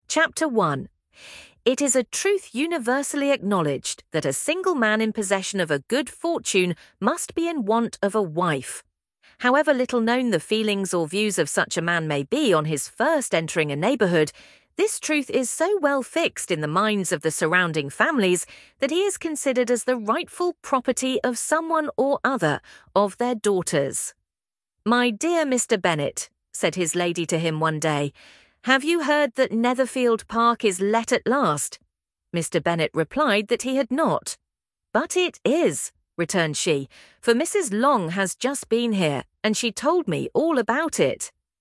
We selected the Neural voice for the three following services: Microsoft Azure, Google Gemini, Amazon Polly while selecting the standard voices for Eleven Labs’ and OpenAI.
• Eleven Labs Sample:
ElevenLabs_clip.mp3